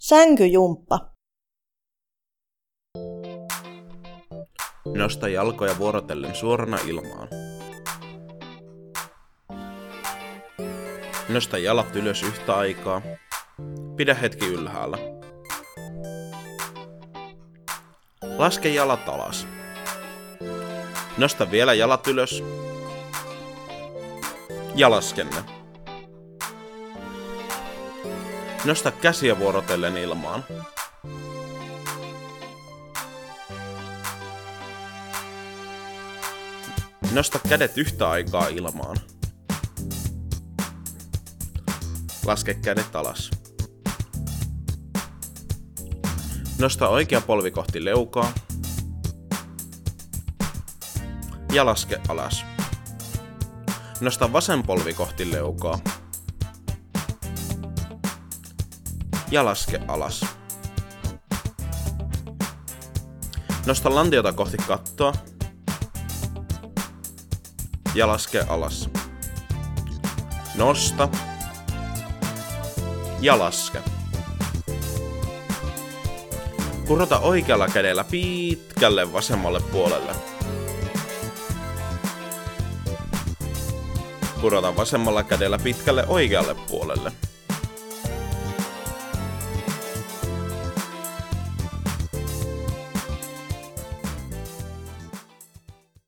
TOM-hankkeessa on valmistettu Muistityökirja "Muistin tähäre" ja siihen liittyvä äänikirja.